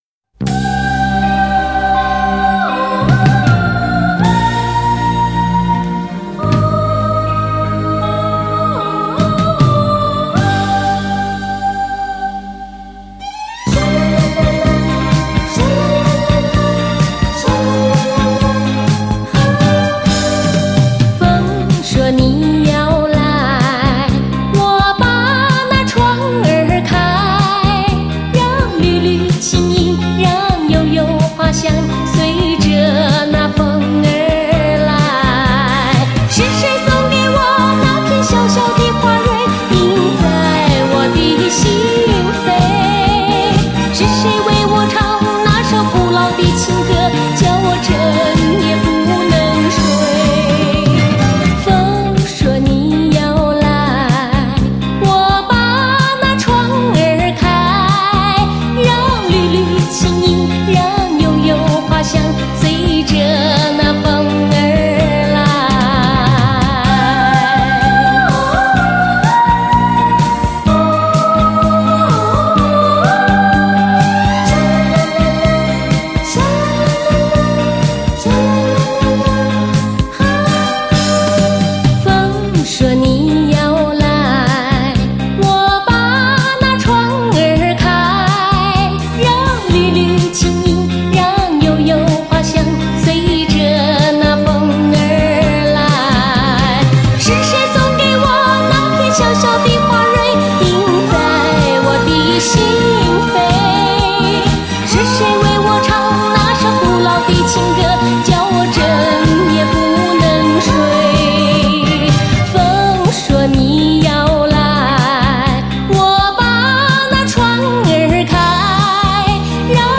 邻家女孩的形象，恬淡的性情和不矫情的演唱风格